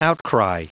Prononciation du mot outcry en anglais (fichier audio)
Prononciation du mot : outcry